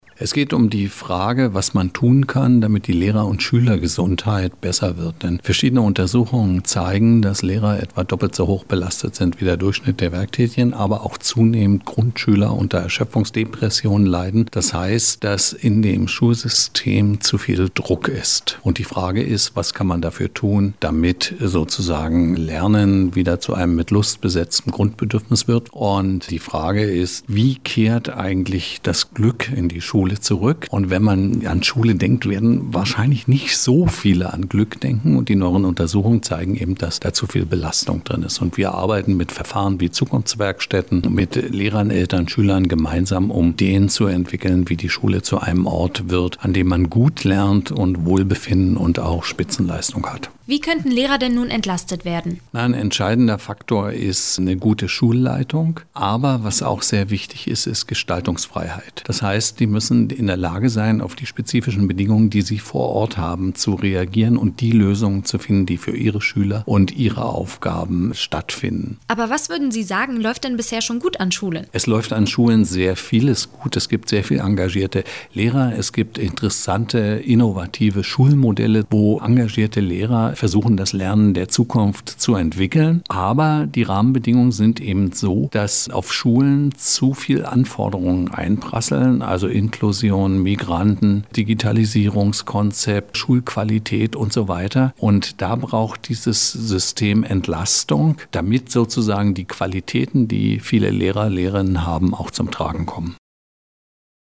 Vortrag zur „guten und gesunden Schule“ am RSI